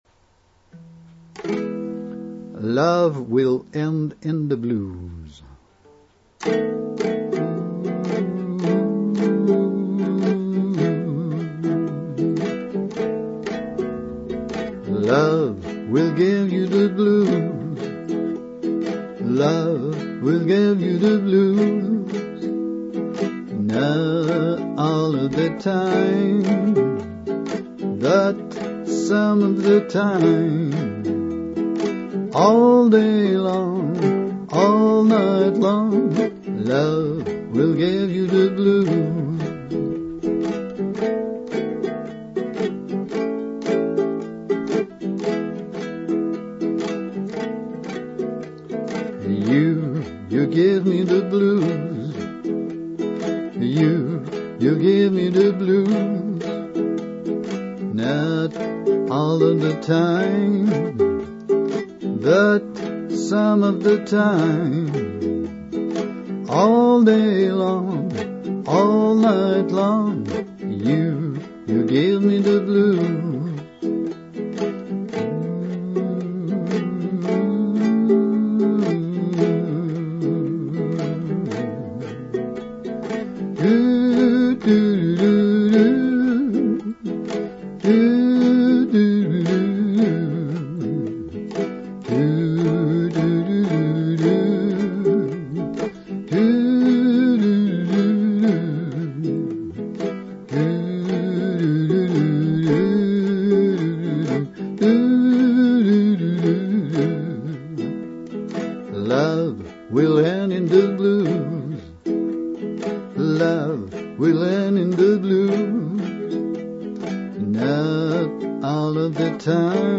lovewillendinthebluesuke.mp3
Intro: 4 bars (key of E) (V7)2__7_2_/(IV7)4__1_6_/(I)1__5_1_/(V7)5__~~/ (I)Love will give you the /blues.
Interlude: 12 bars solo (improvisation)